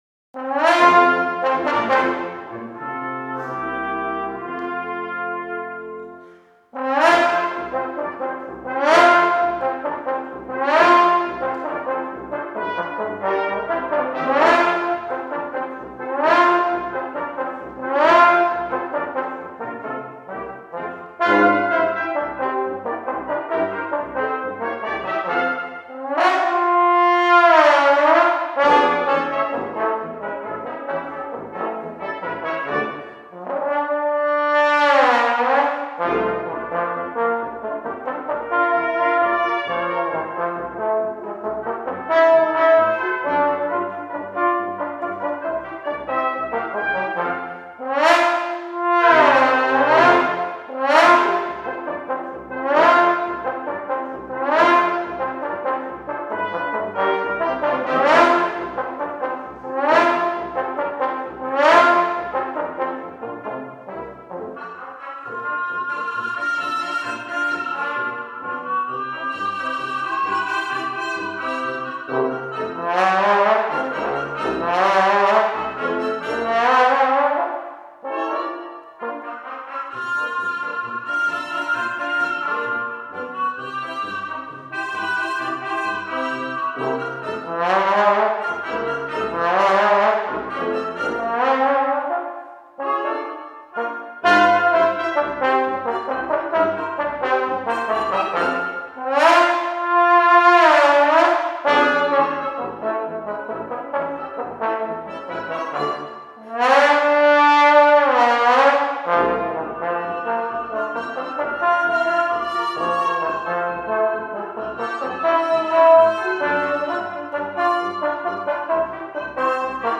For Brass Quintet
Trombone feature.